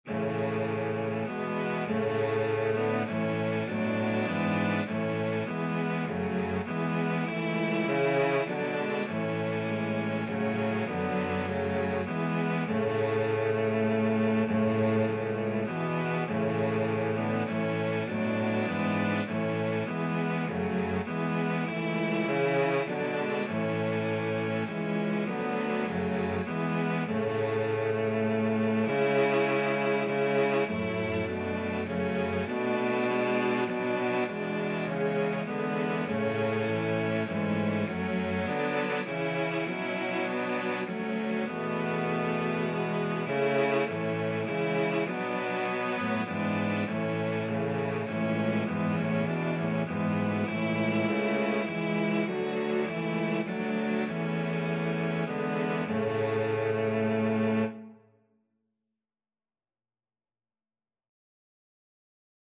Christian Christian Cello Quartet Sheet Music Love Divine, All Loves Excelling
Free Sheet music for Cello Quartet
Cello 1Cello 2Cello 3Cello 4
A major (Sounding Pitch) (View more A major Music for Cello Quartet )
3/4 (View more 3/4 Music)
Classical (View more Classical Cello Quartet Music)